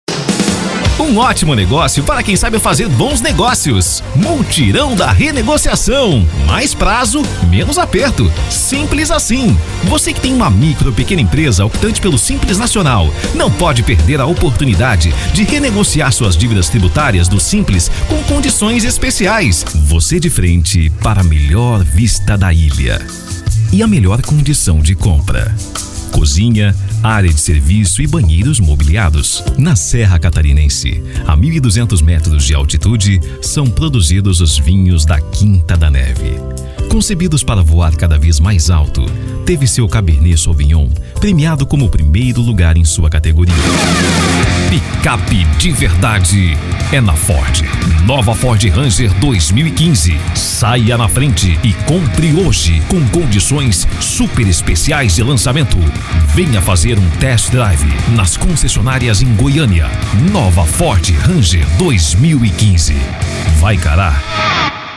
Spot Comercial
Vinhetas
Animada